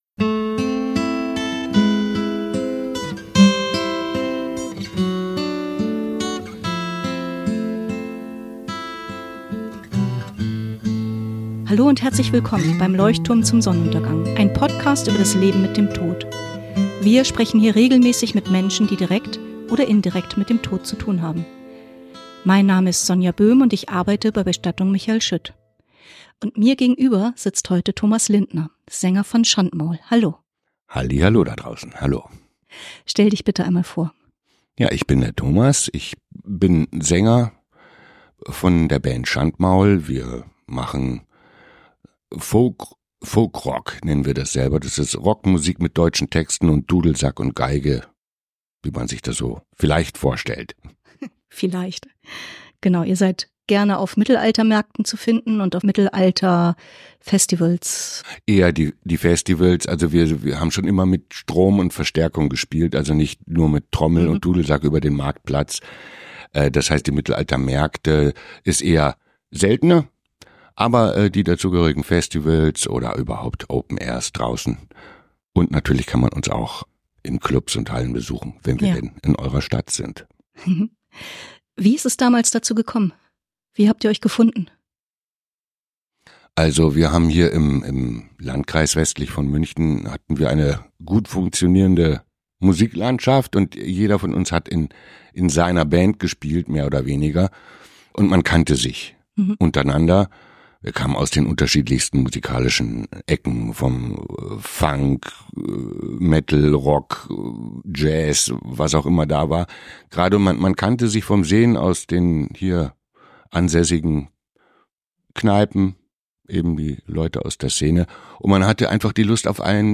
Im Gespräch mit dem Sänger von Schandmaul geht es um das Lied “Euch zum Geleit” und um seinen Weg aus seiner Krankheit.